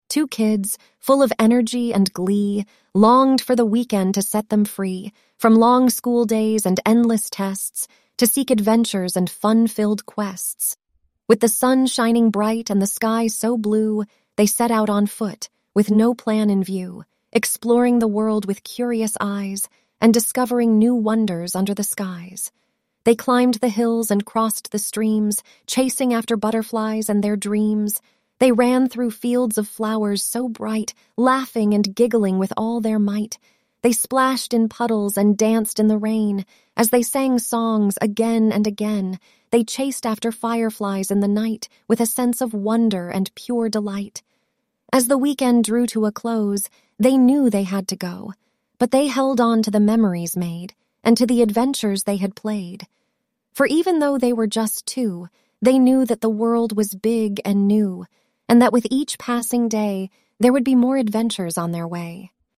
I love it when # ElevenLabs unexpectedly goes completely off the rails. I tried writing a thing like it was giving a speech at the opening of a hotel and getting stuff wrong. Of course I put in some keyboard mashing to see what it would do, and it just loses it. It’s imotions go all over the place.